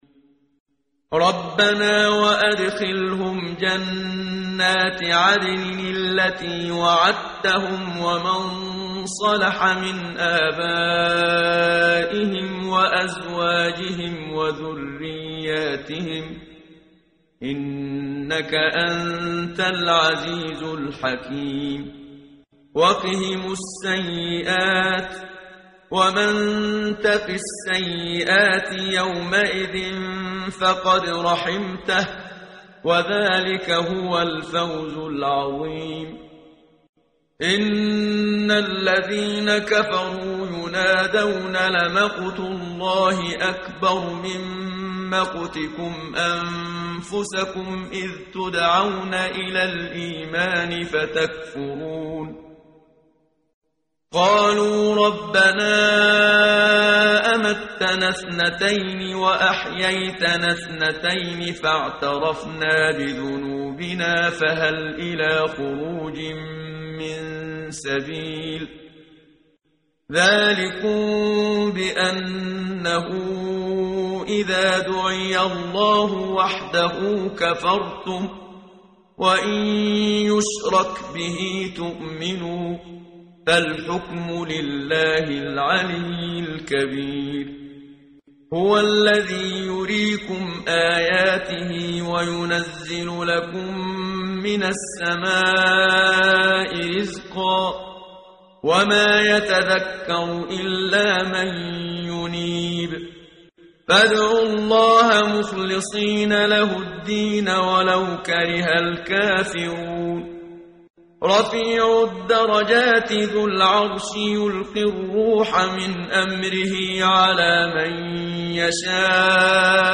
قرائت قرآن کریم ، صفحه ۴۶۸ ، سوره مبارکه «غافر» آیه ۸ تا ۱۶ با صدای استاد صدیق منشاوی.